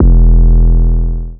808s
808 (Kut Throat).wav